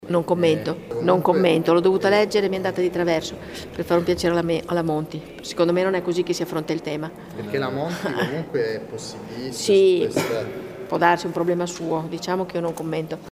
La collega Frascaroli ha letto di malavoglia queste parole, sentiamola